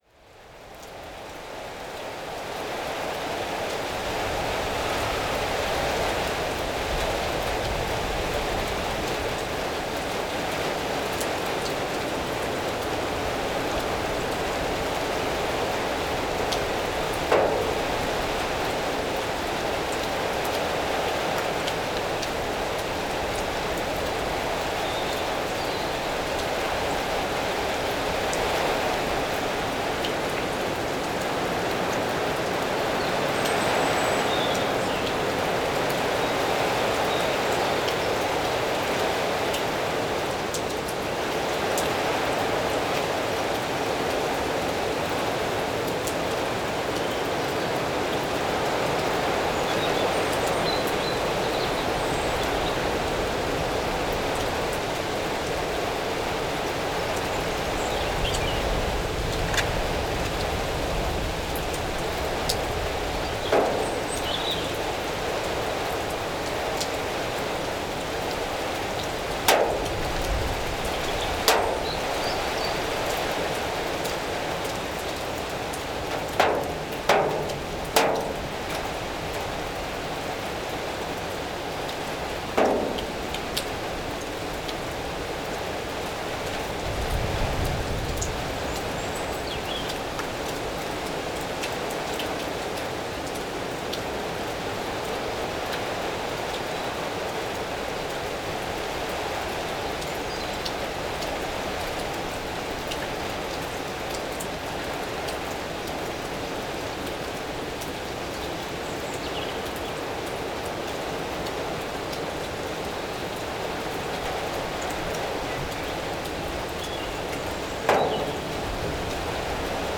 Paisagem sonora de chuva sobre paragem de autocarro na Rua da Paz, Barreiros a 30 Março 2016.
NODAR.00574 – Barreiros e Cepões: Chuva sobre paragem de autocarro na Rua da Paz em Barreiros